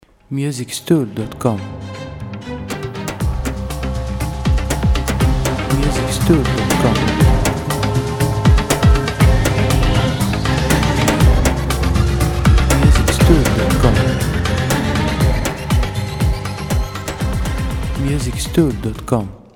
• Type : Instrumental
• Bpm : Allegro
• Genre : Oriental / Folk & Funky